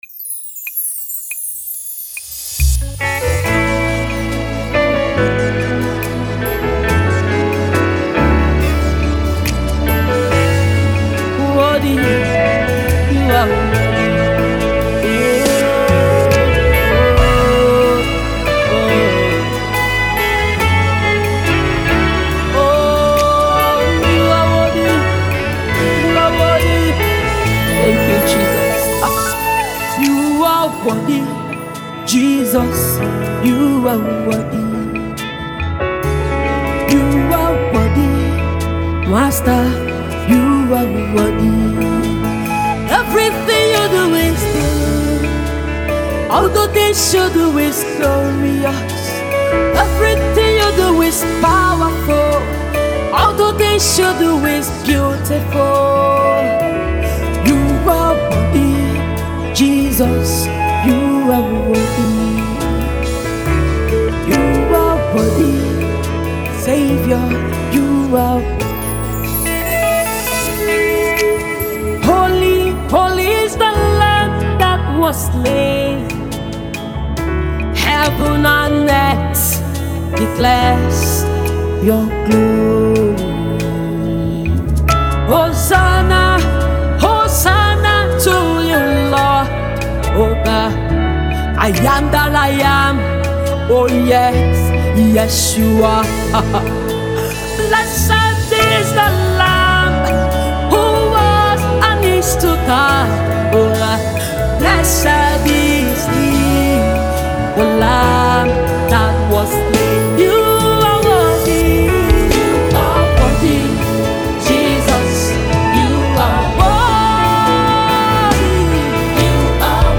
Gospel music
Contemporary Gospel singer